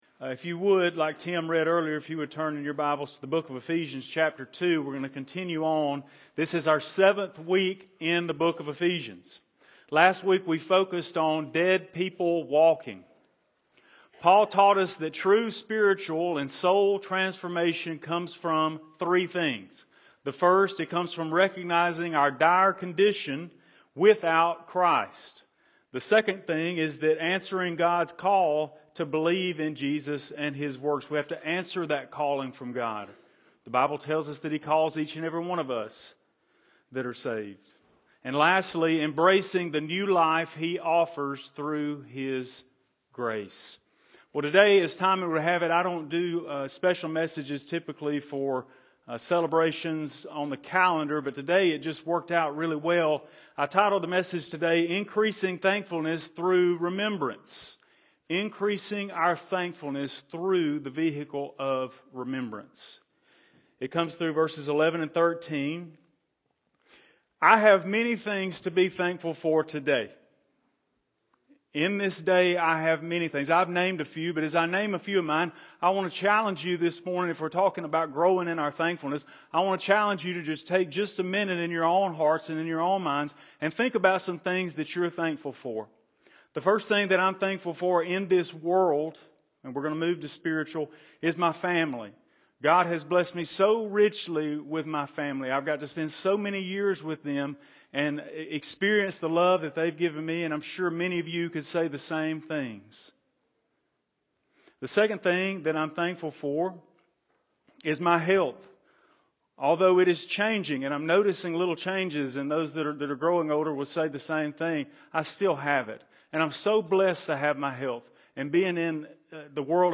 Increasing Thankfulness Through Remembrance | Ephesians 2:11-13 | Sunday Sermon